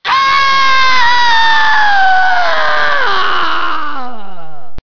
Play Sonya Blade Scream MK4 - SoundBoardGuy
sonya-blade-scream-mk4.mp3